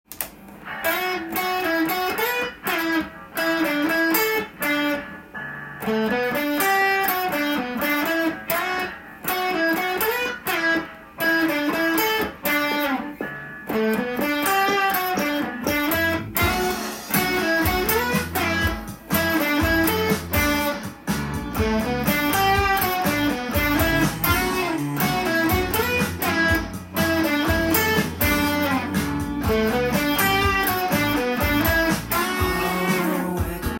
音源にあわせて譜面通り弾いてみました
keyがDになるのでDメジャースケールで構成されています。
印象的なのはスライドの多さです。